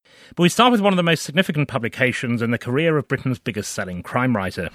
Let me give you some more examples, this time by native speakers:
Can you hear the /t/ in most  or biggest? No you can’t, because it isn’t there.